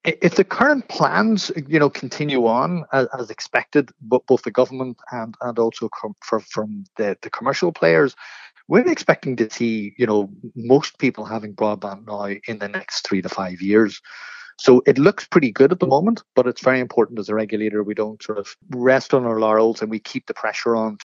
ComReg Commissioner Garrett Blaney says there’s no reason maximum coverage can’t soon be achieved…………..